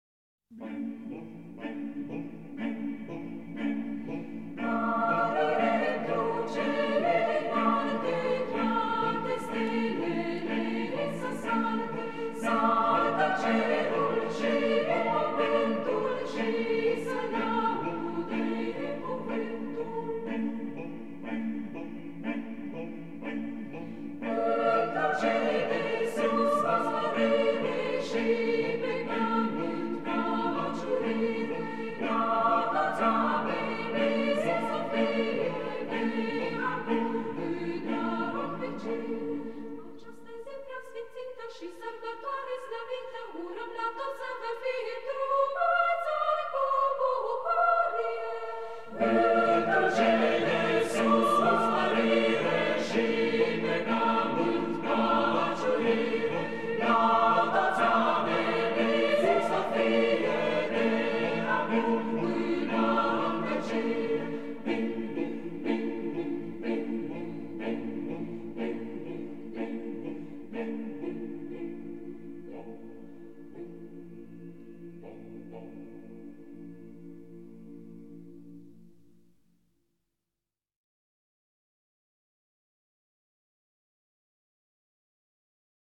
„Mărire’ntru cele’nalte” (Nicolae Lungu) de pe albumul „ROMANIAN MADRIGAL CHAMBER CHOIR” interpretat de Corul Naţional de Cameră MADRIGAL – dirij. MARIN CONSTANTIN.